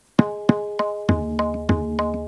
Rupak_Sample2.wav